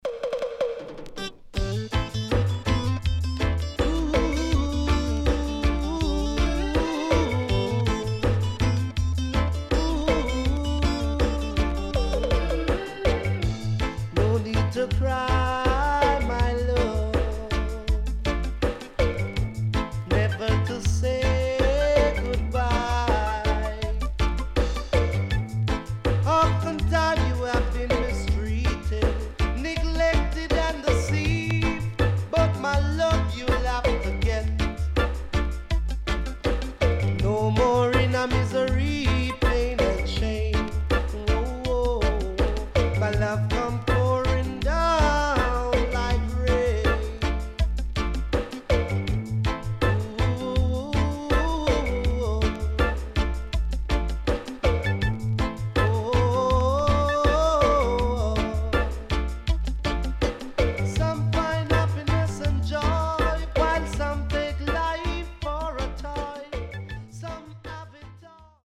HOME > REISSUE USED [DANCEHALL]
SIDE A:少しノイズ入りますが良好です。